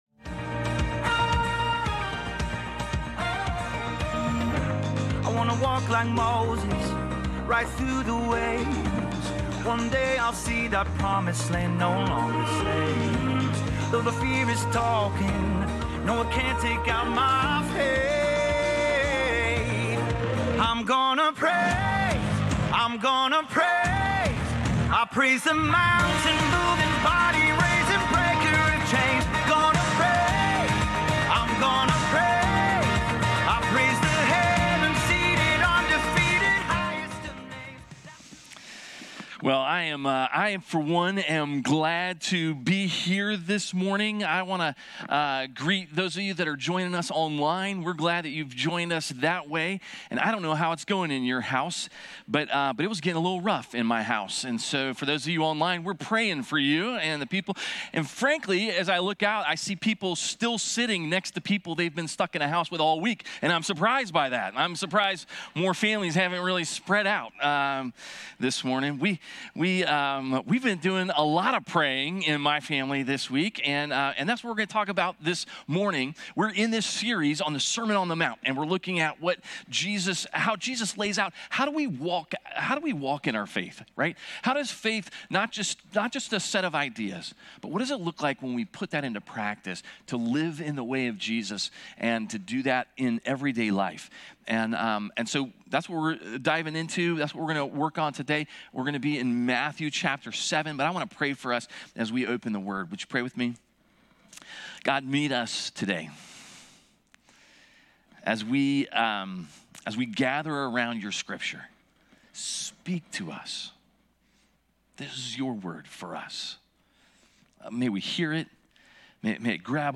Sermons | Advent Presbyterian Church